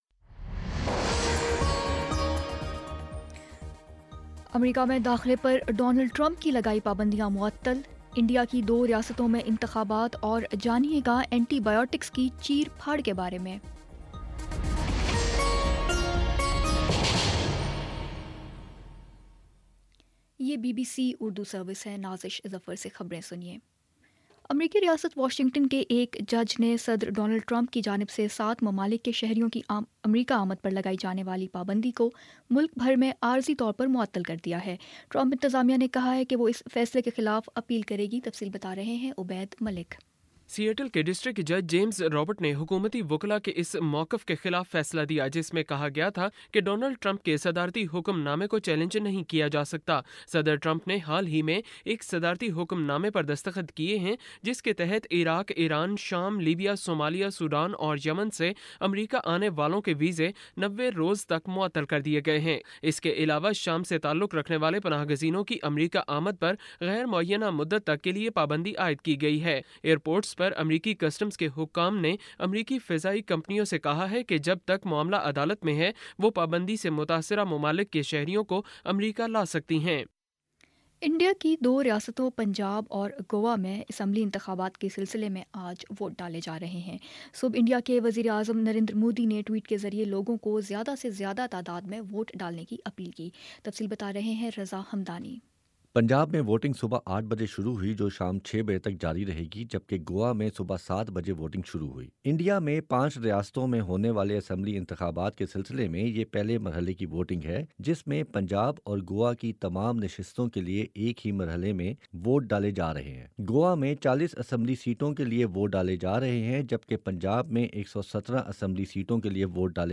فروری 04 : شام پانچ بجے کا نیوز بُلیٹن